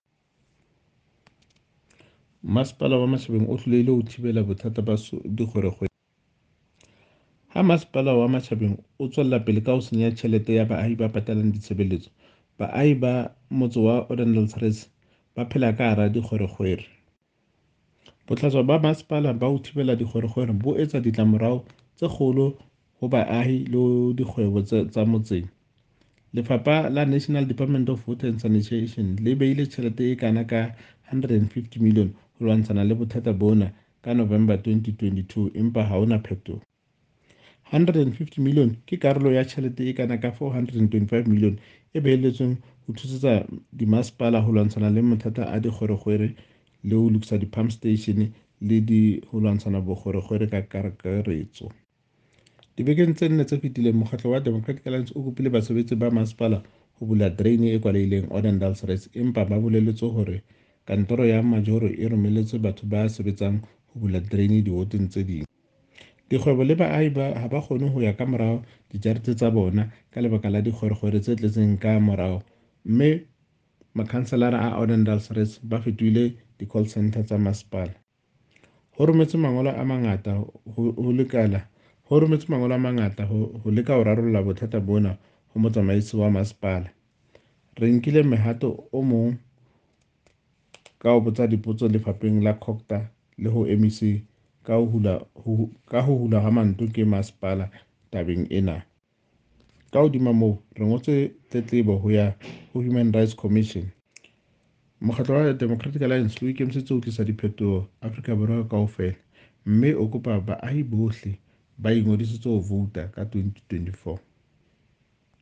Sesotho by Cllr Thabiso Lethlake.
Sotho-voice-Thabiso.mp3